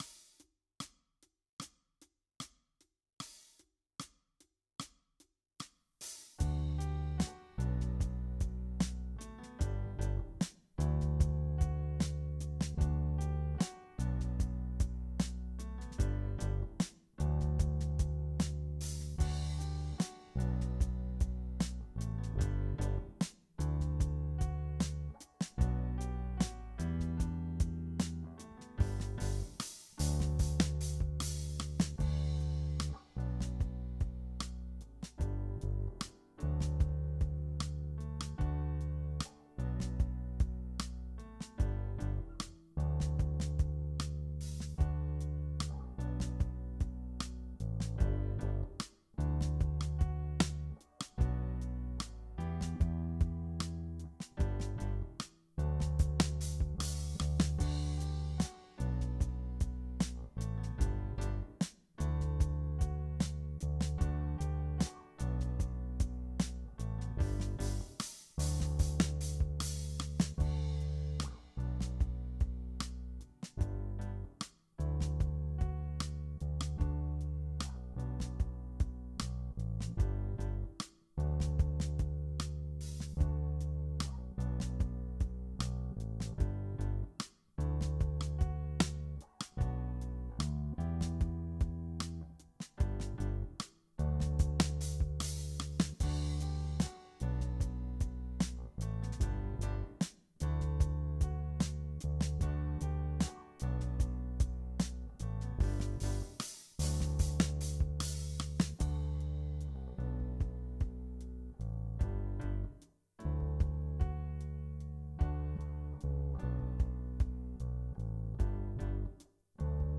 JamTrack
Jam track